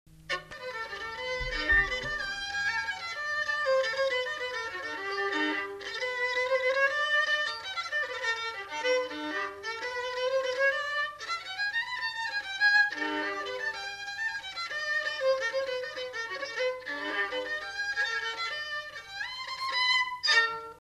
Aire culturelle : Lugues
Lieu : Casteljaloux
Genre : morceau instrumental
Instrument de musique : violon
Danse : rondeau